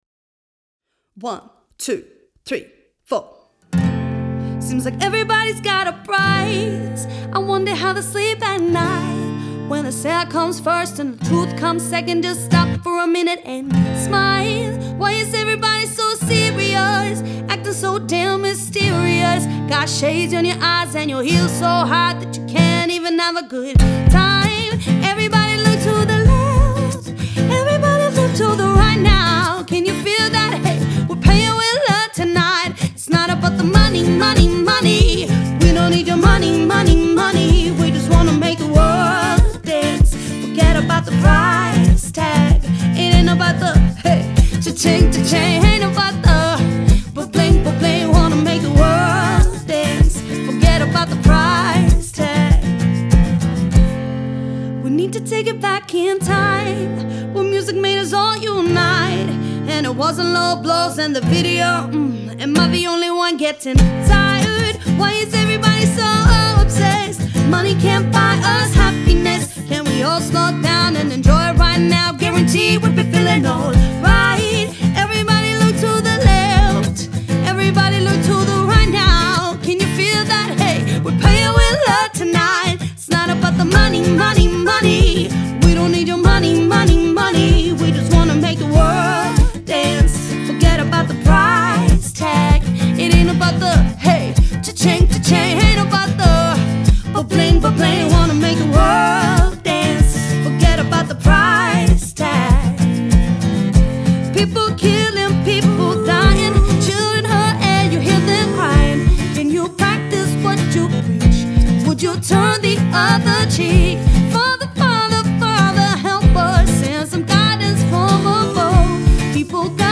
Dual Vocals | Guitar | DJ | MC